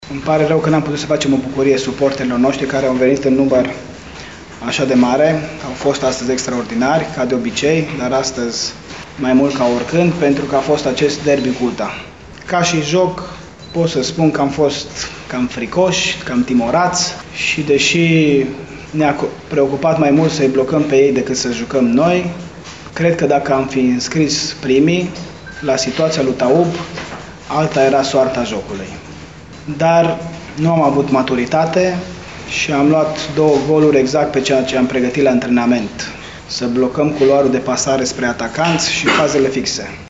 Iată declarația sa despre derbiul vestului: